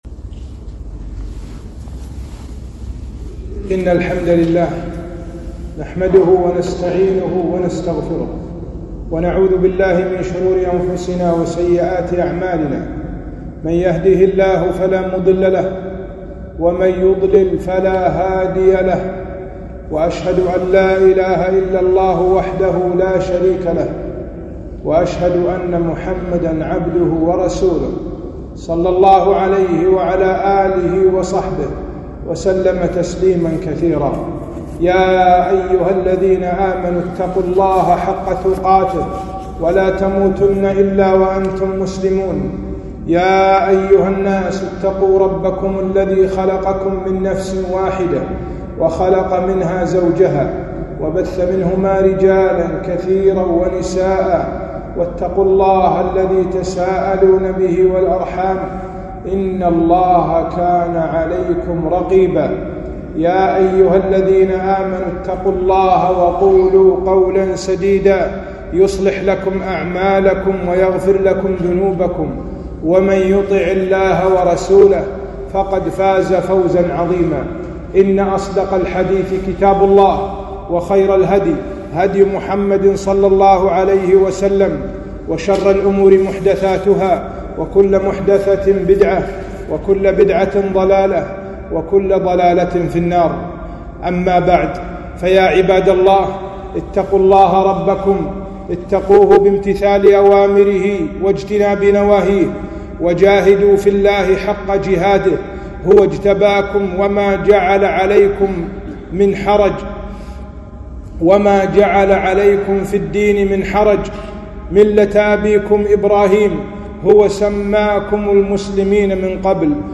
خطبة - العبرة من غزوة الأحزاب